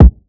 Kick (YourSelf).wav